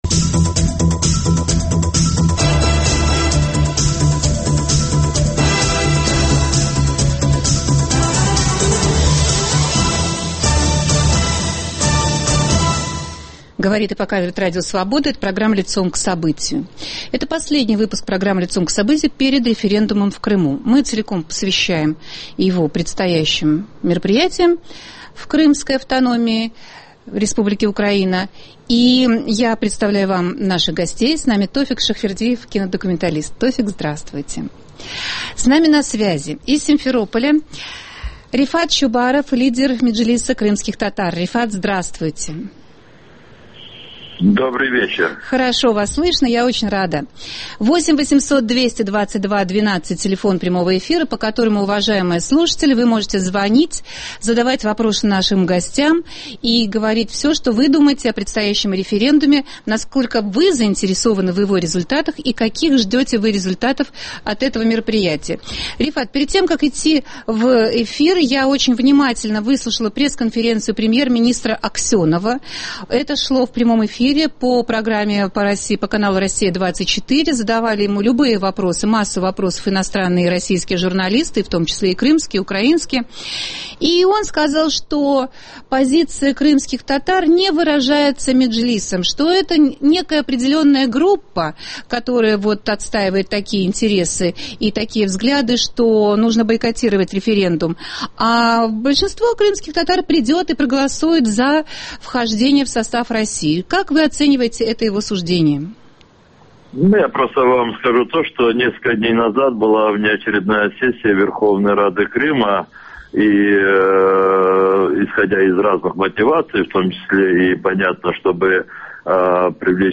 Глава Меджлиса крымских татар Рефат Чубаров, журналист Аркадий Бабченко и кинодокументалист Тофик Шахвердиев в разговоре о том, правда ли, что все русские хотят присоединения новой территории? И правда ли, что все крымчане мечтают отколоться от Украины?